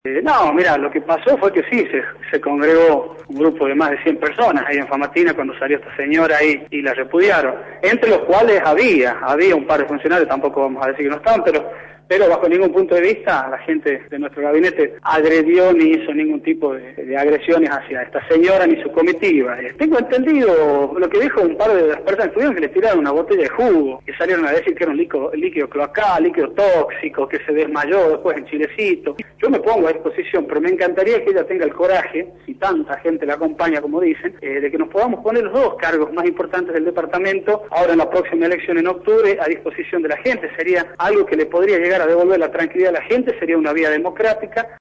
Ismael Bordagaray, intendente de Famatina, por Radio La Red